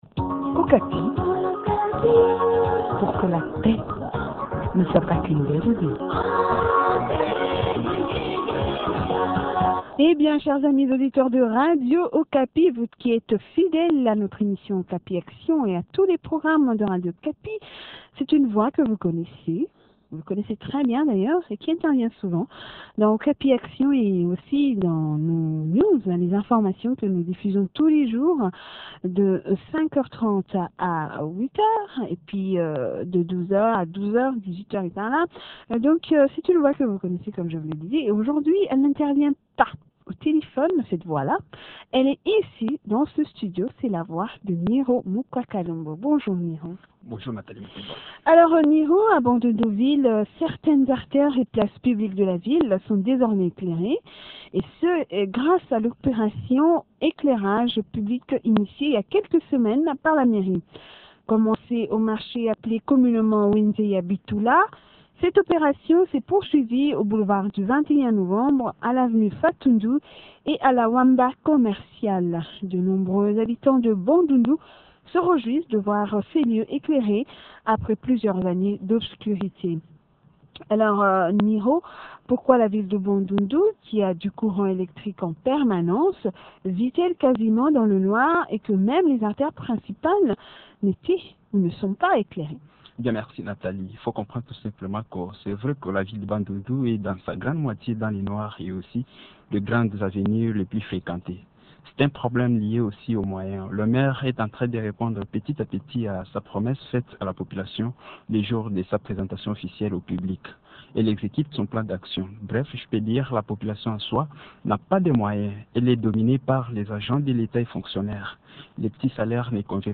reçoit Martine Bokenge, maire de la ville de Bandundu.